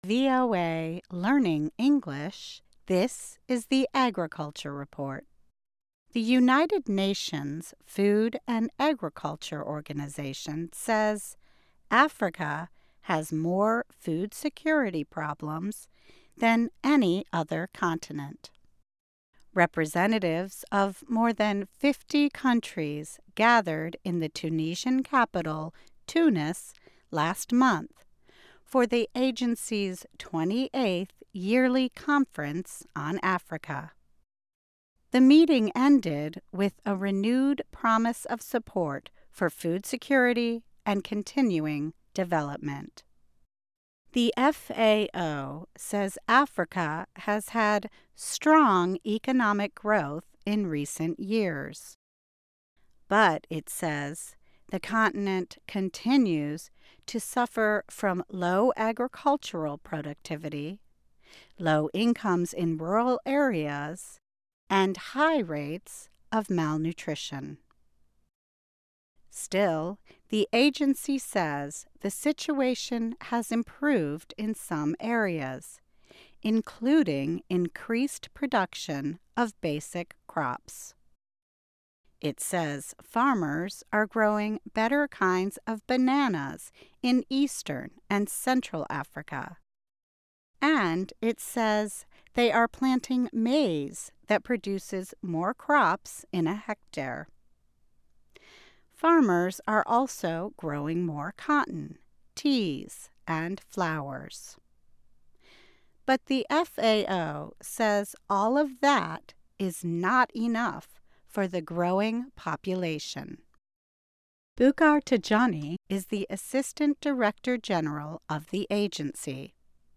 Learn English as you read and listen to a weekly show about farming, food security in the developing world, agronomy, gardening and other subjects. Our stories are written at the intermediate and upper-beginner level and are read one-third slower than regular VOA English.